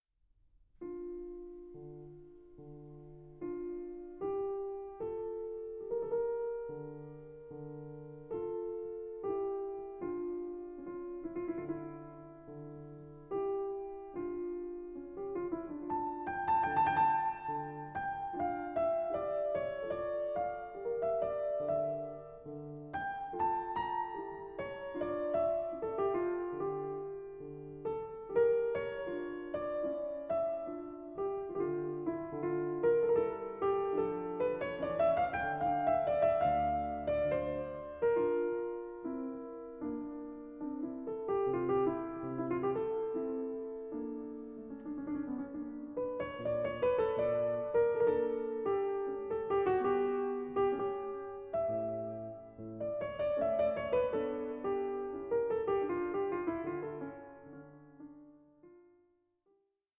RE-ESTABLISHING A RICH PIANISTIC TRADITION
pianist